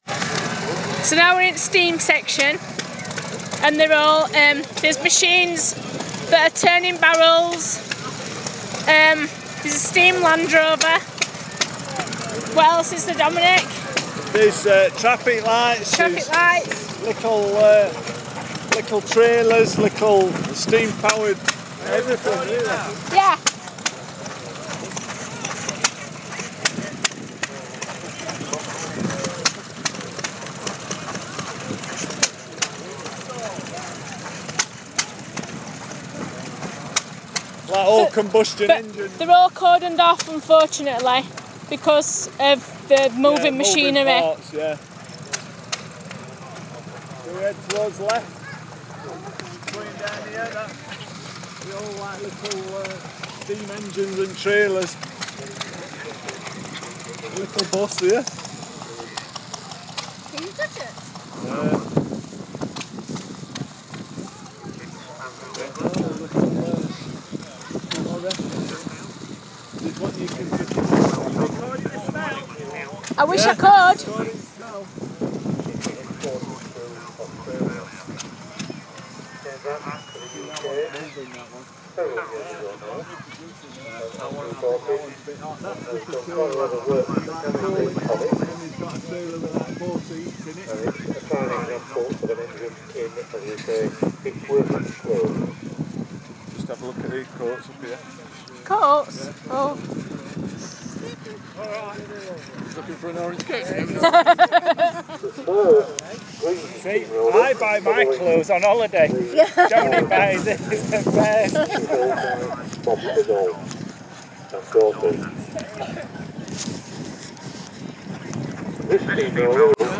steam engines